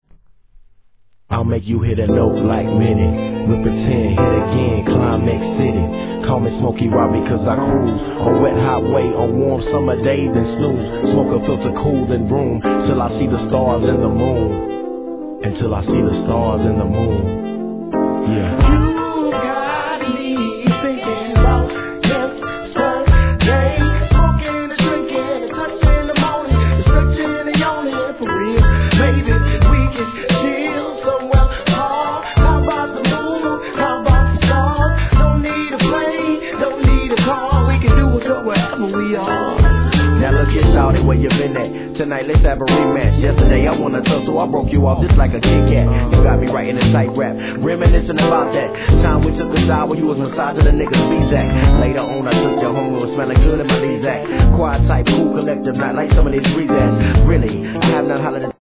HIP HOP/R&B
JAZZYでムーディーなトラック、そして大人なRAPとソウルフルなコーラス♪